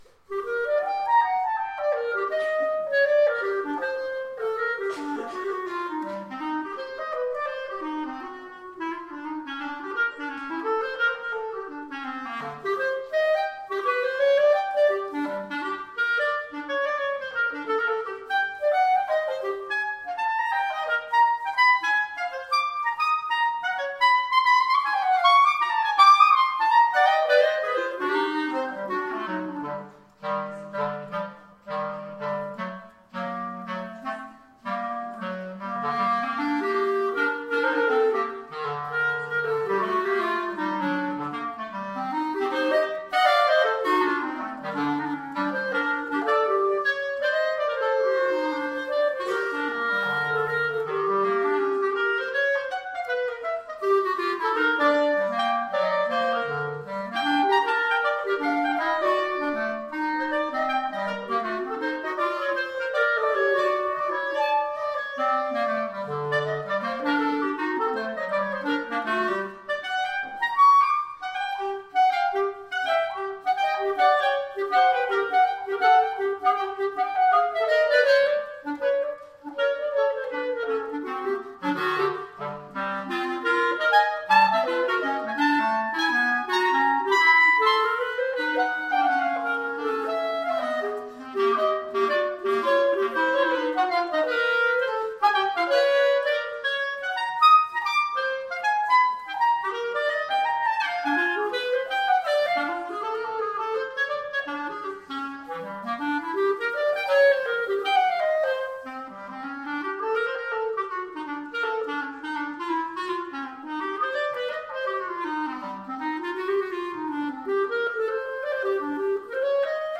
They are from the coffee house event we held as a fundraiser at Mount Calvary back in may of 2010, so they’re about 3 years old, but just now seeing the light of day.
clarinet